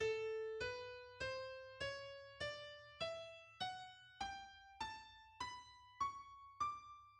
The sheng (Chinese: ) is a Chinese mouth-blown polyphonic free reed instrument consisting of vertical pipes.
Traditional sheng
Its scale is mainly diatonic, for example the 17-pipe (4 of which are silent decorative pipes) sheng used in Jiangnan sizhu is tuned:[5]